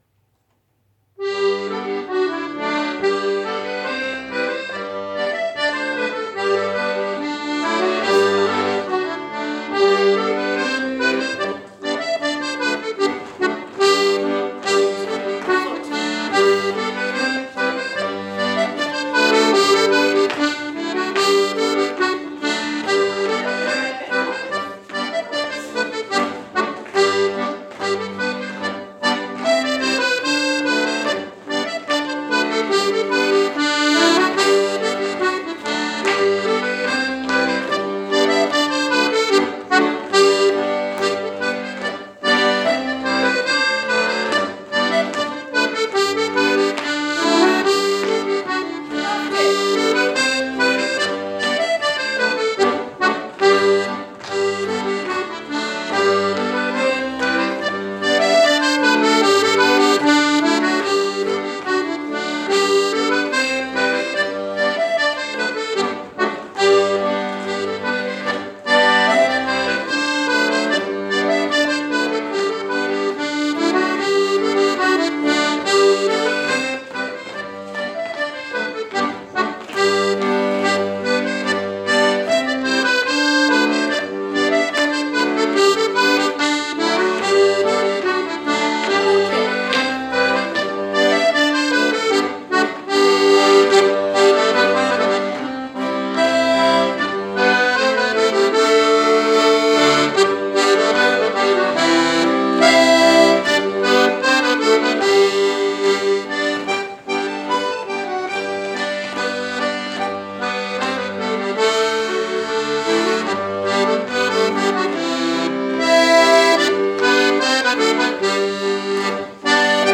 • Melodeons (D/G)
• Fiddles
At the beginning of 2019 we embarked on a project of recording our practices so that the tunes can be used for personal music practice purposes. These recordings are of the whole dance (including the 'Once To Yourself', but not the walk on or walk off).